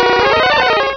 Cri de Crustabri dans Pokémon Rubis et Saphir.